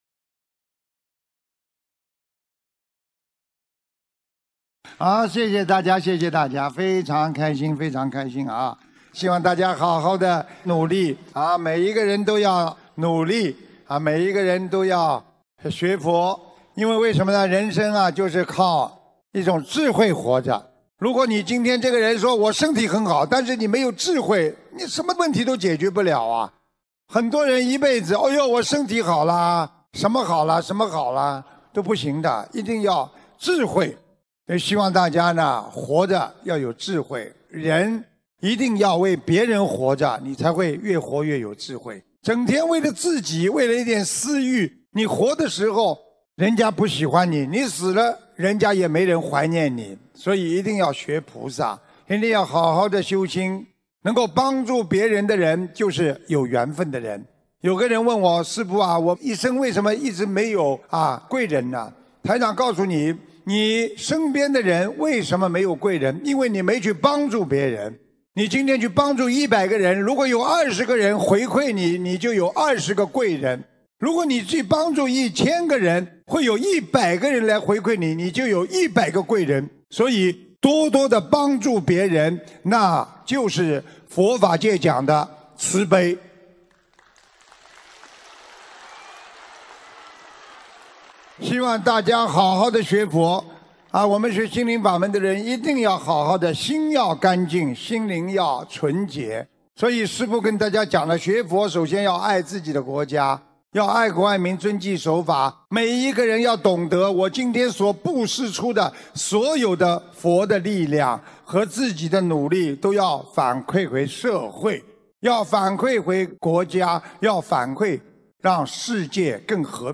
2020年1月12日悉尼法会结束语-感人开示节选